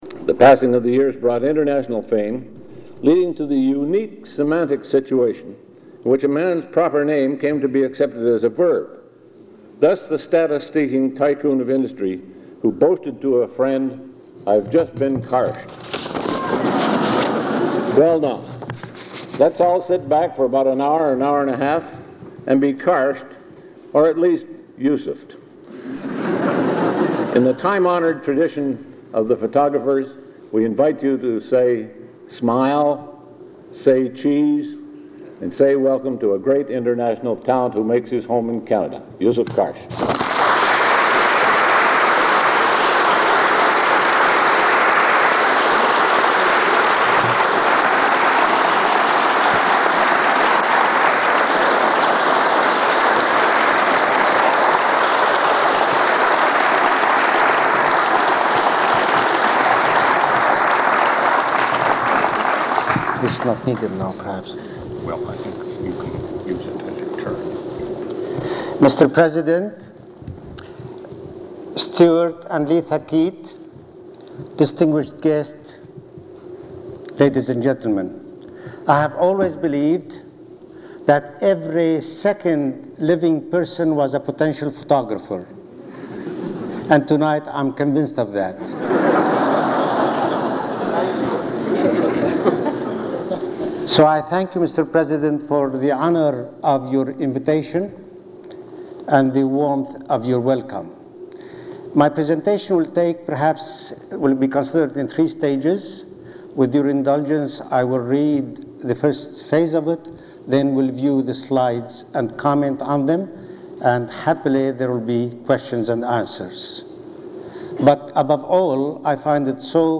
Item consists of a digitized copy of an audio recording of a Vancouver Institute lecture given by Yousuf Karsh on February 25, 1978.